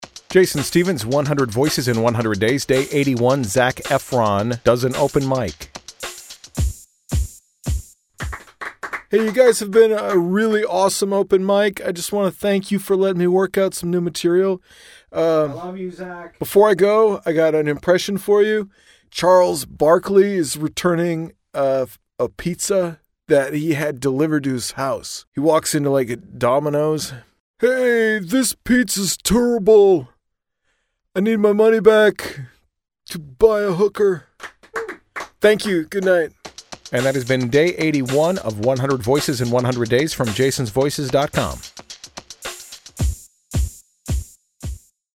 Tags: celebrity voice overs, voice actor sound alike, Zac Efron impression